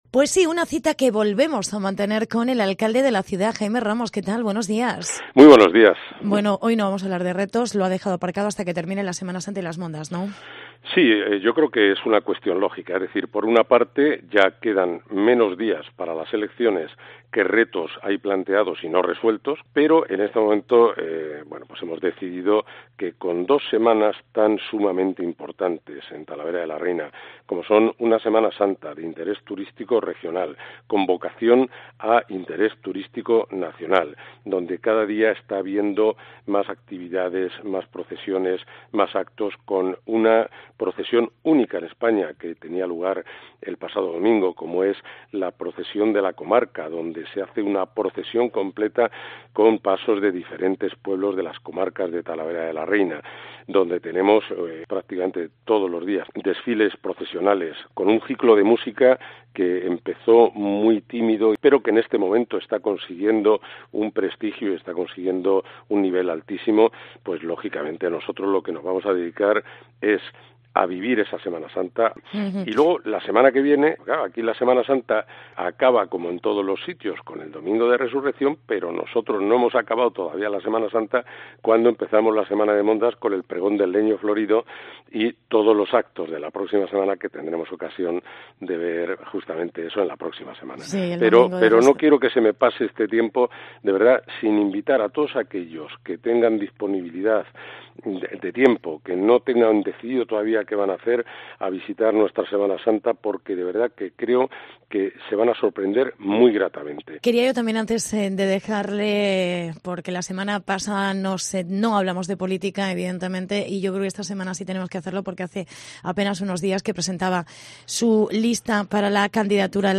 Semana Santa, Mondas y política. Mezcla de ingredientes en Talavera. Entrevista con el alcalde Jaime Ramos